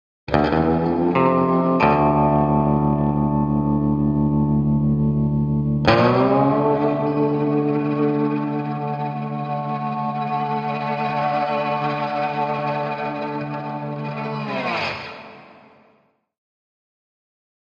Electric Guitar Twang - Texas Melody 3, (Slide Guitar)